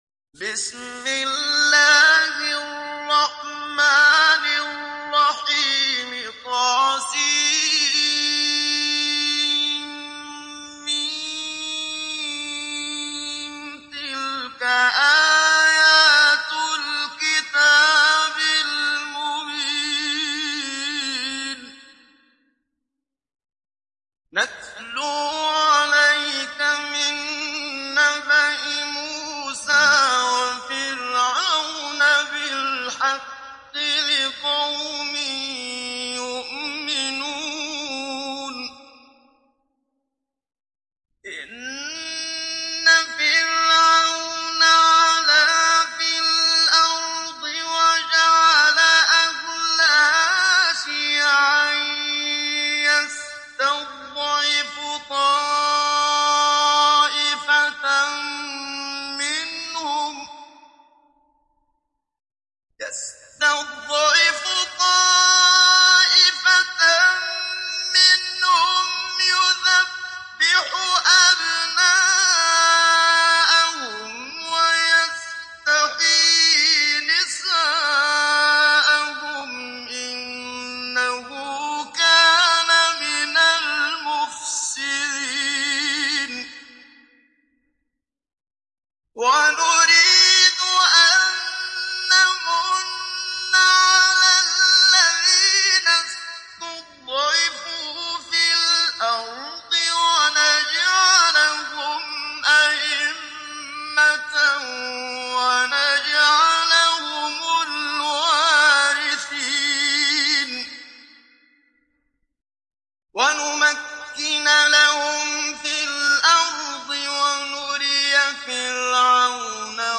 Kasas Suresi İndir mp3 Muhammad Siddiq Minshawi Mujawwad Riwayat Hafs an Asim, Kurani indirin ve mp3 tam doğrudan bağlantılar dinle
İndir Kasas Suresi Muhammad Siddiq Minshawi Mujawwad